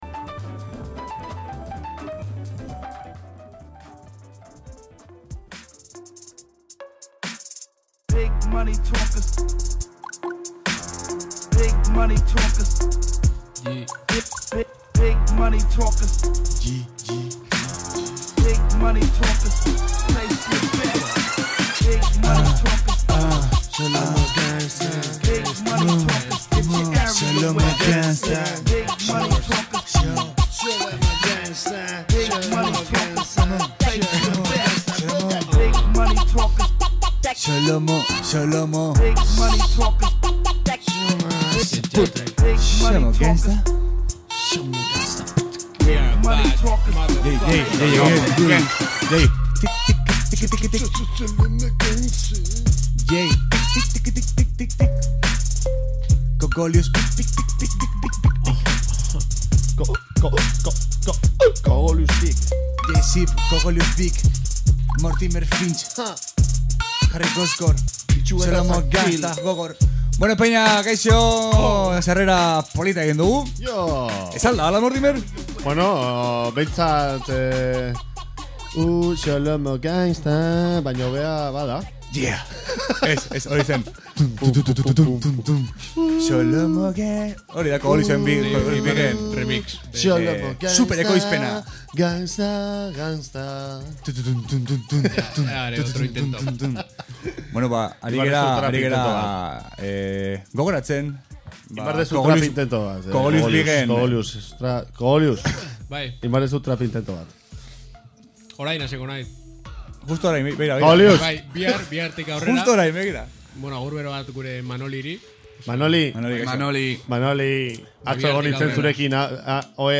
Euskal Herriko eta nazioarteko rap musika izan da entzugai Xolomo Gangsta saioan.